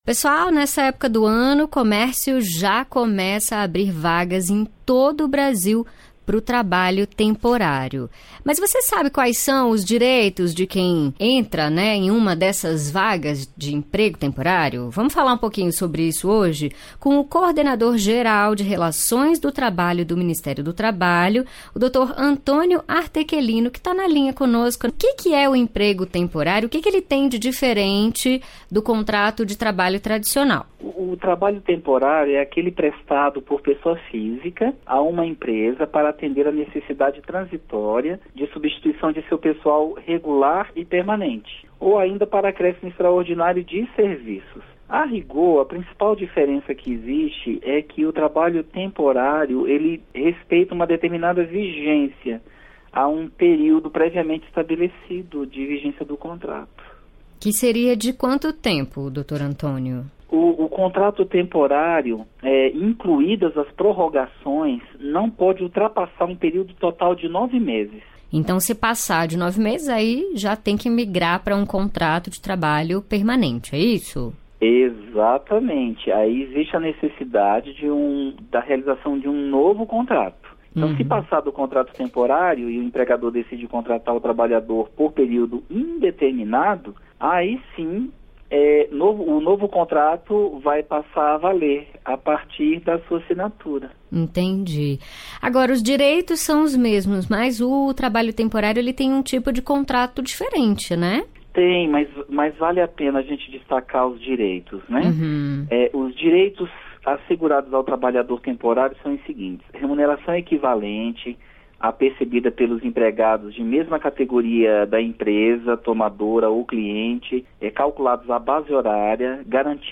Entrevista: Saiba quais são os direitos de quem ocupa uma vaga temporária